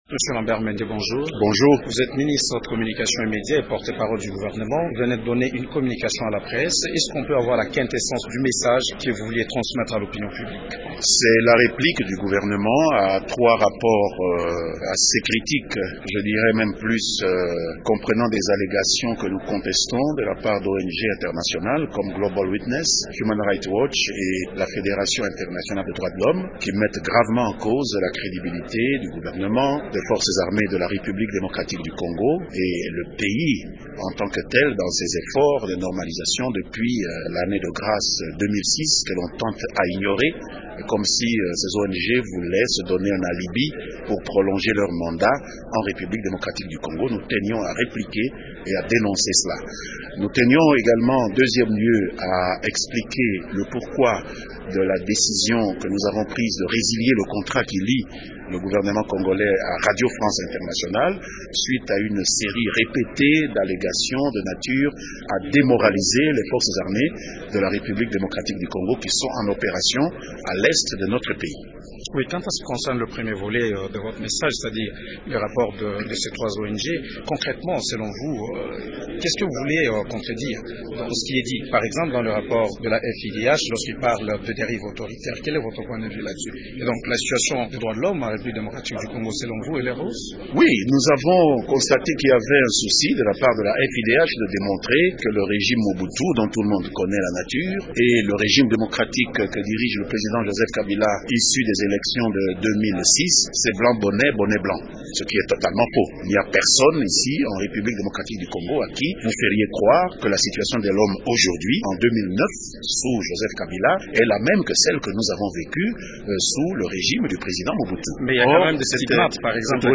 Lambert Mende, qui est notre invité du jour, a donné la position du gouvernement dans une conférence de presse qu’il a animée au Grand Hôtel Kinshasa.